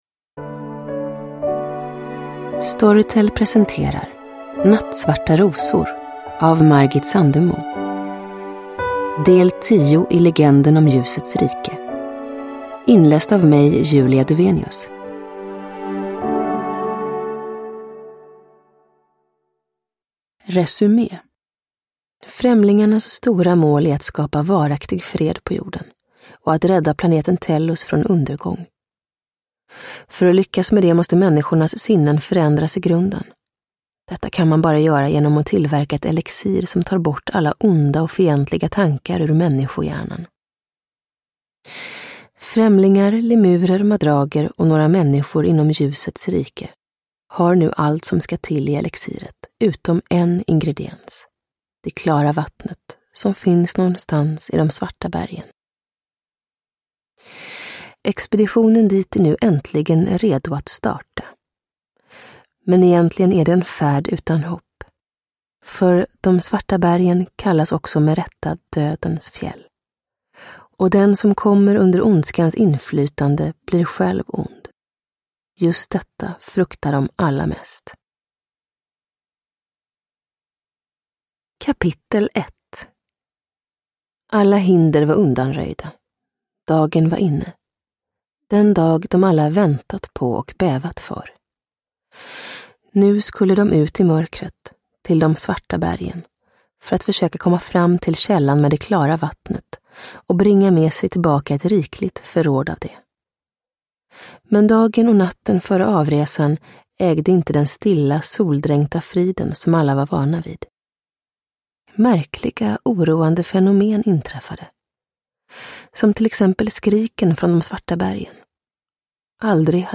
Nattsvarta rosor – Ljudbok – Laddas ner